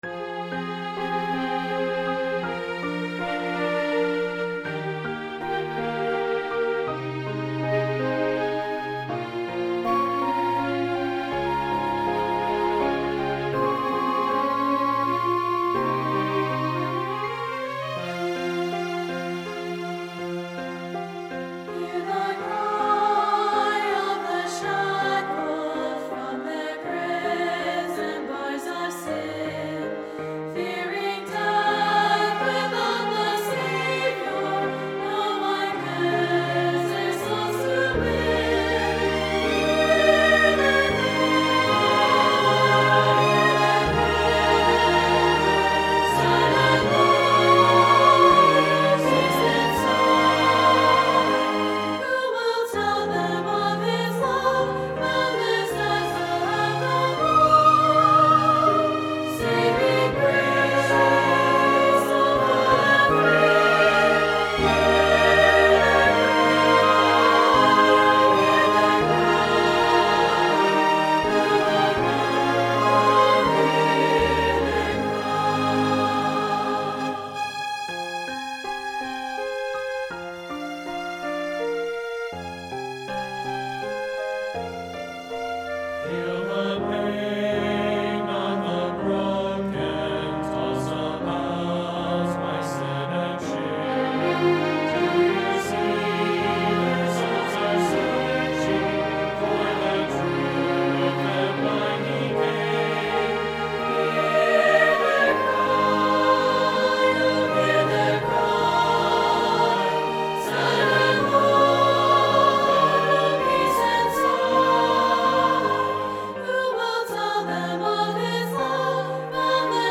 SATB and Piano
Choral
Anthem
Church Choir